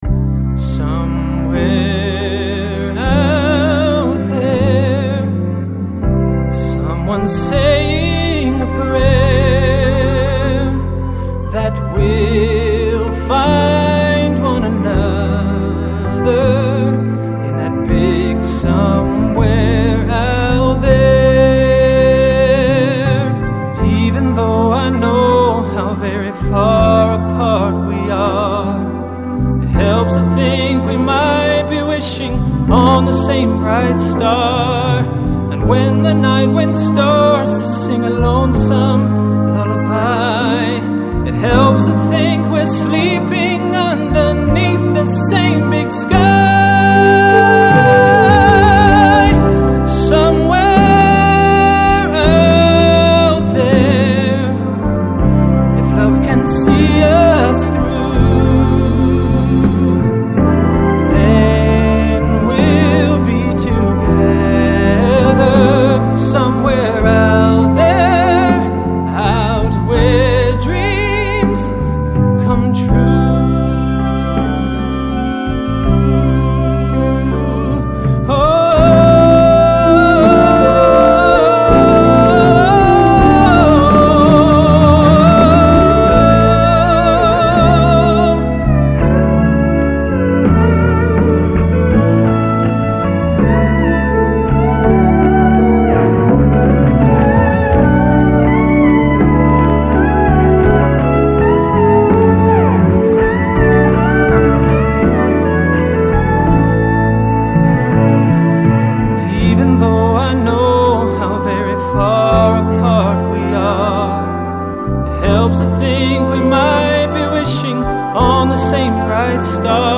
tenor voice